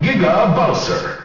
Category:Announcer calls (SSBM) Category:Giga Bowser (SSBM) You cannot overwrite this file.
Giga_Bowser_English_Announcer_SSBM.wav